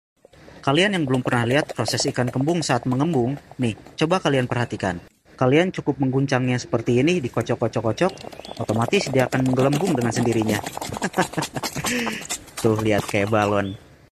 Ikan Kembung ketika mengembang ada sound effects free download
Ikan Kembung ketika mengembang ada suara pompanya